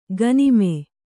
♪ ganime